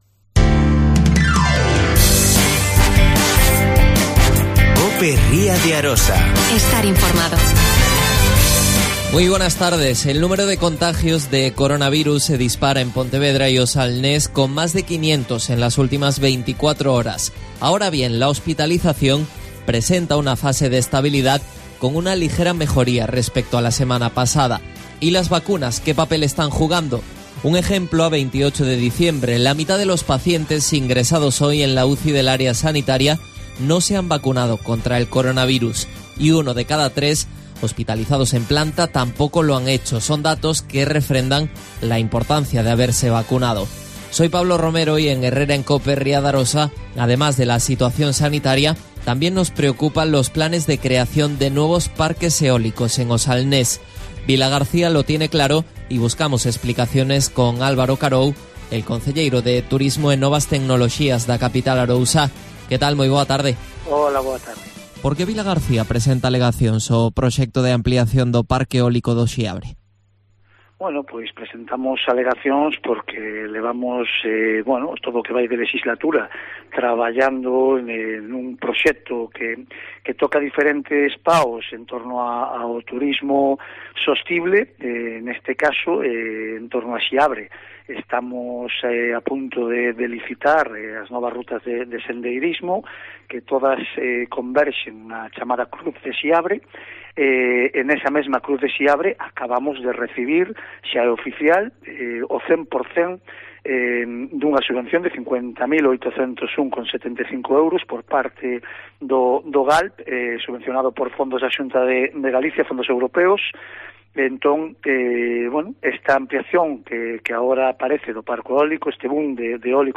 AUDIO: Álvaro Carou. Concejal de Turismo y Nuevas Técnologias en el Ayuntamiento de Sanxenxo.